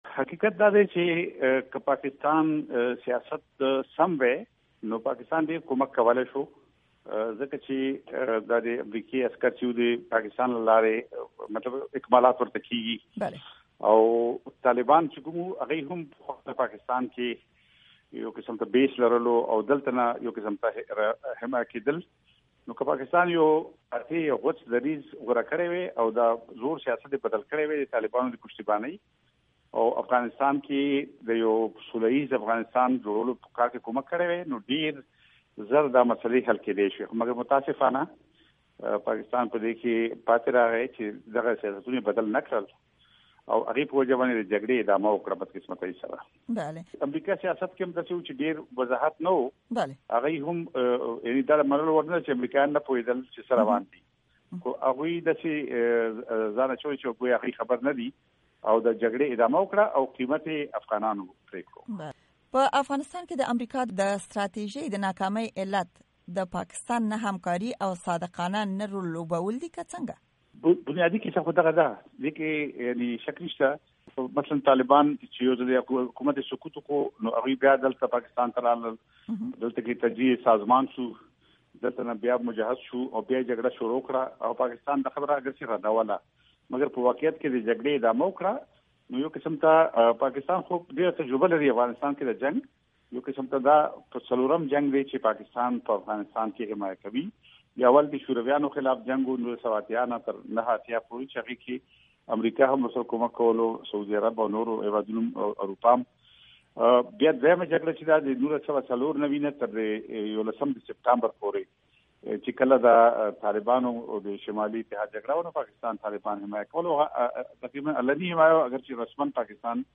مرکې
امريکا غږ سره د افراسياب خټک مرکه